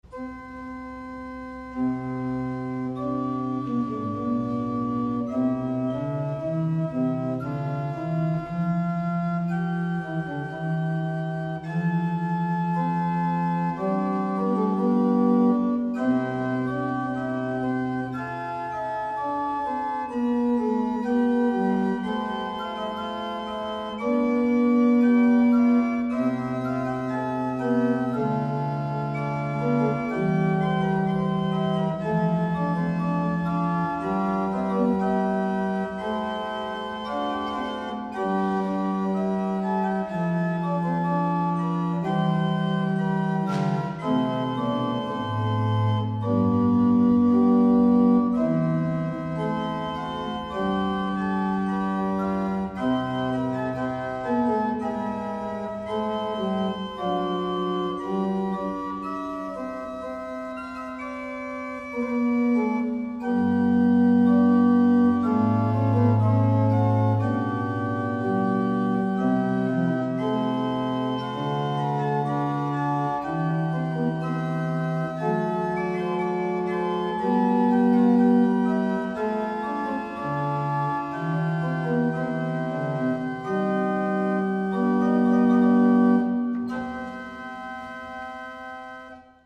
Orgel der Iglesia de San Pedro, Granada